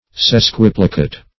Sesquiplicate \Ses*quip"li*cate\, a. [Sesqui- + plicate.]